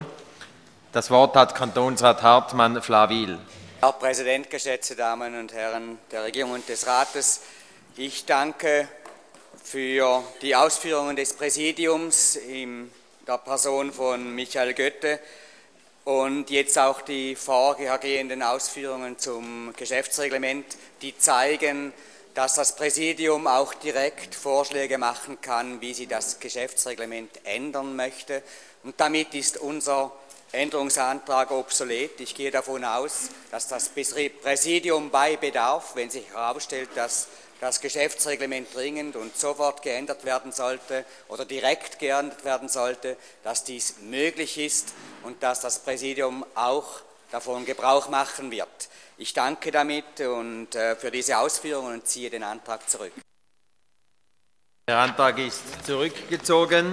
27.2.2013Wortmeldung
Session des Kantonsrates vom 25. bis 27. Februar 2013